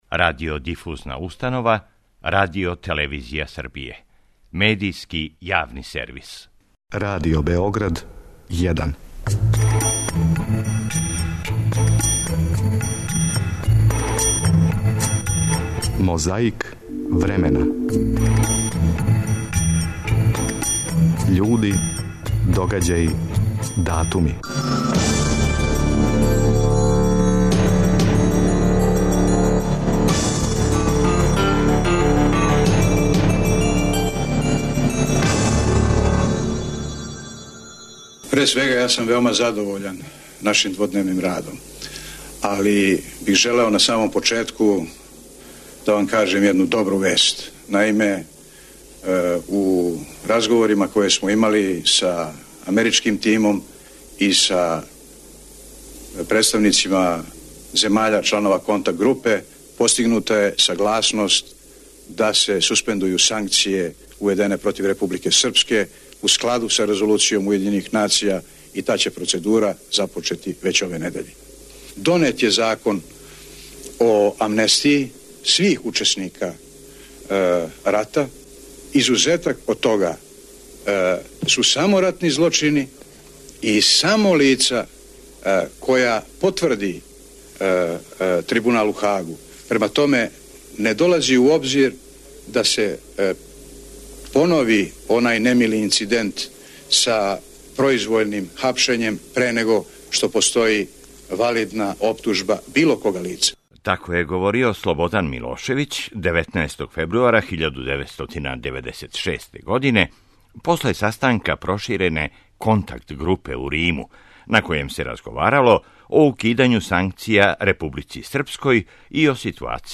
У Београду је 14. фебруара 1961. године одржан протестни митинг поводом убиства Патриса Лумумбе, првог председника владе независног Конга.
На Првом програму Радио Београда, у емисји о домаћим издајницима, која је емитована 18. фебруара 1973. године, могле су се чути речи Милана Недића и краља Петра II Карађорђевића.